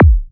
VEC3 Bassdrums Trance 39.wav